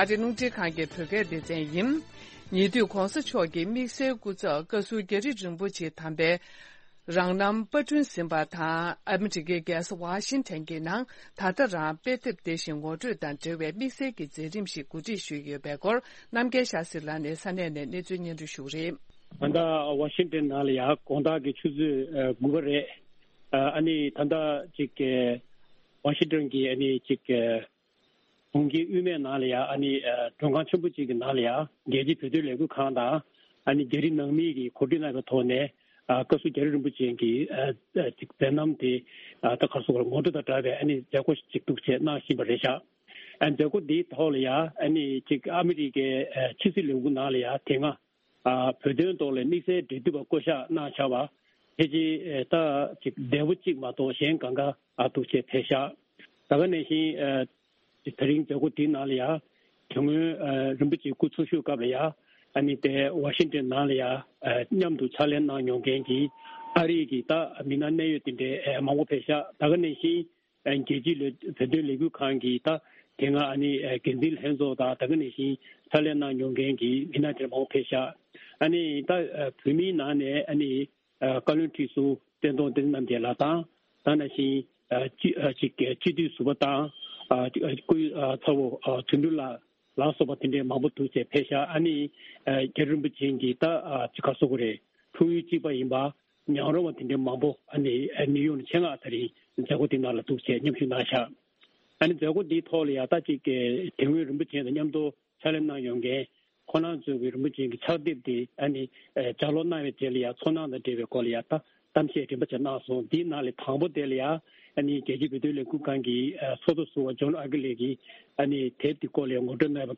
ས་གནས་ནས་སྙན་སྒྲོན་གནང་གི་རེད།